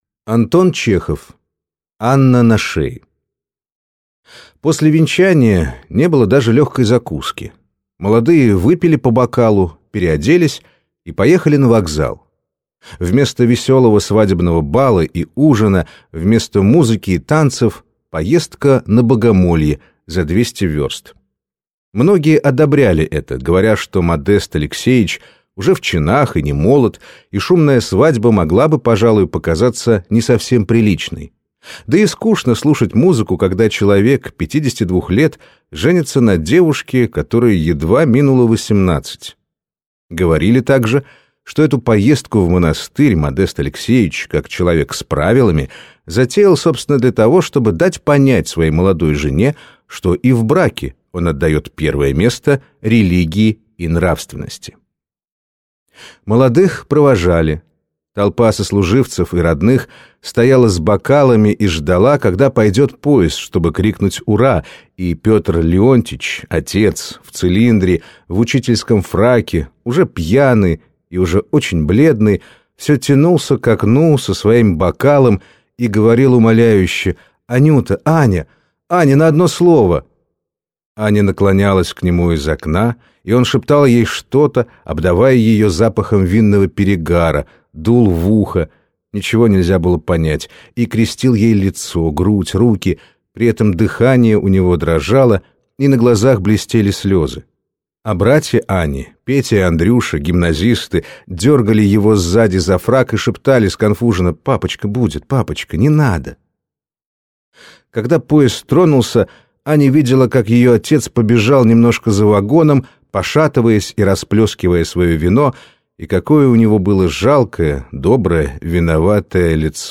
Аудиокнига Анна на шее | Библиотека аудиокниг
Прослушать и бесплатно скачать фрагмент аудиокниги